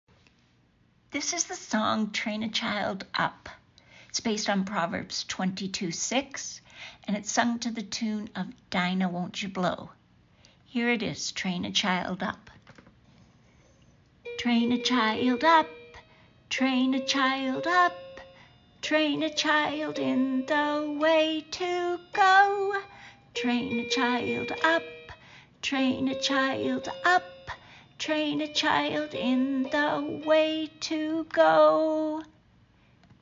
Train a Child Up! (Sung to the tune of Dinah, Won’t You Blow ) / Songsheet BIBLE LESSON: (Proverbs 22:6) Using flashcards PDF , teach the Bible verse.